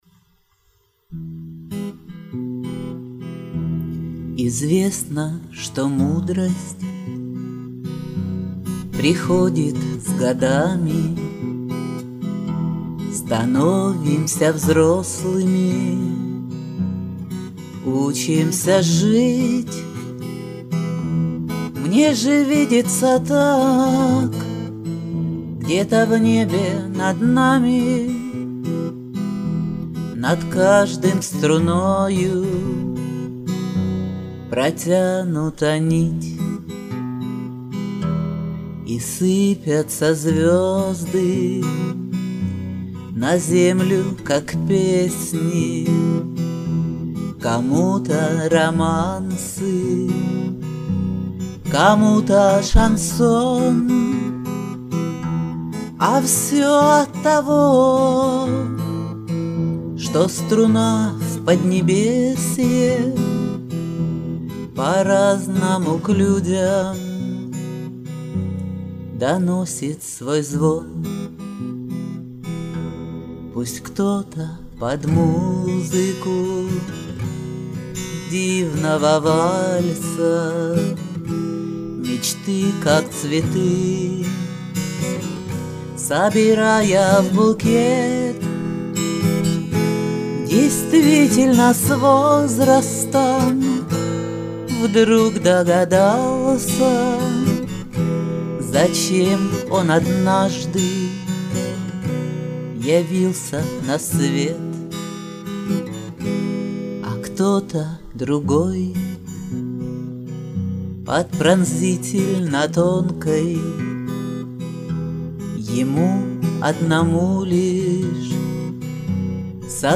Шансон
Записала их по-памяти в домашних условиях.